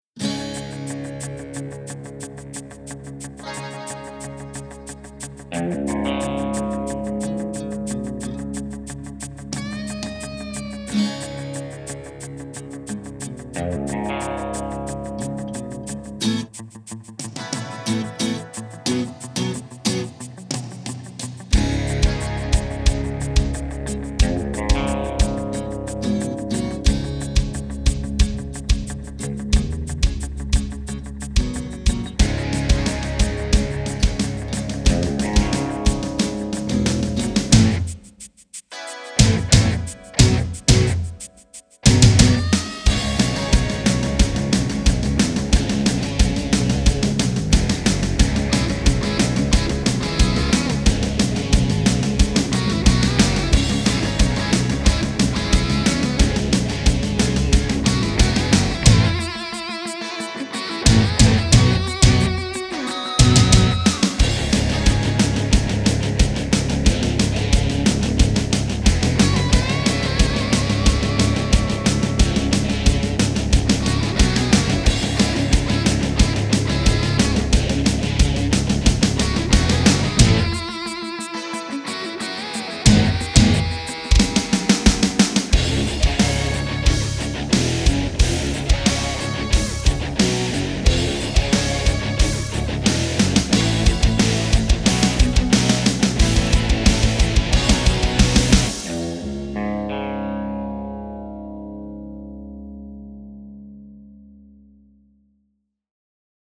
a soundtrack for a fictitious movie